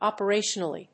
音節op・er・a・tion・al・ly 発音記号・読み方
/ˈɑpɝˈeʃʌnʌli(米国英語), ˈɑ:pɜ:ˈeɪʃʌnʌli:(英国英語)/